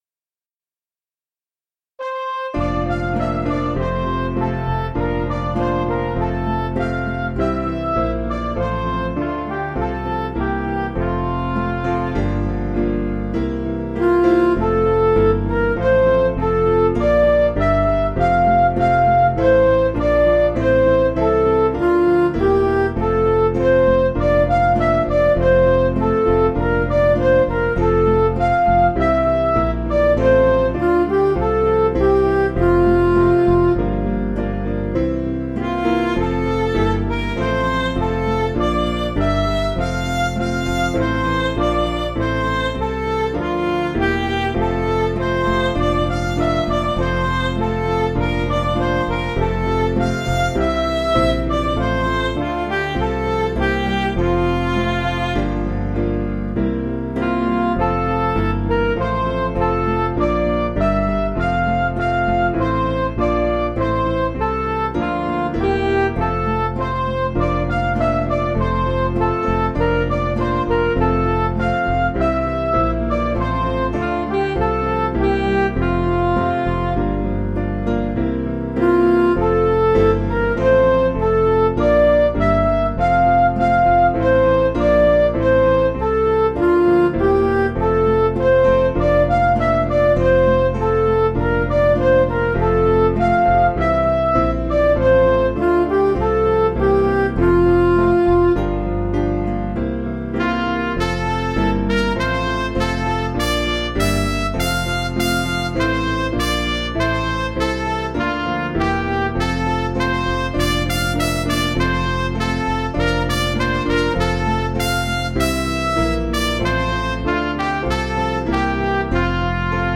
Midi